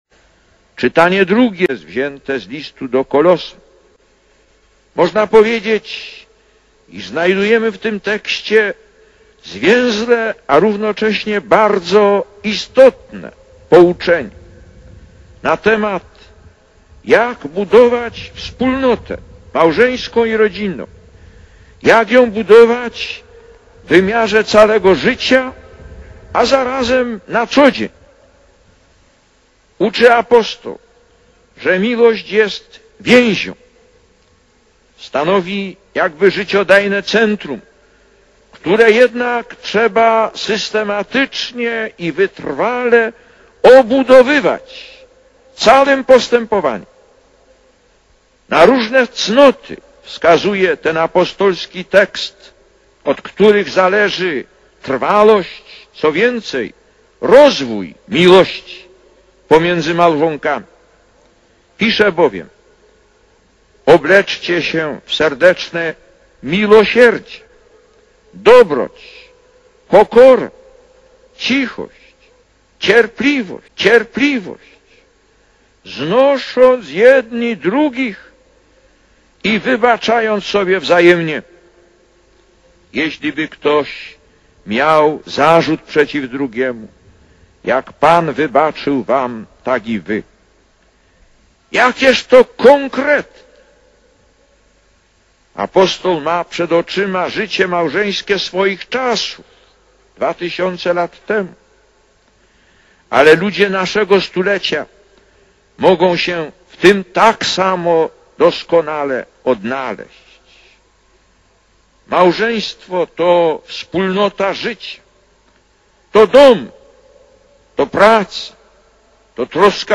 Lektor: Z homilii podczas Mszy św. odprawionej dla rodzin (Szczecin 11 czerwca 1987 – nagranie): „"...że cię nie opuszczę aż do śmierci".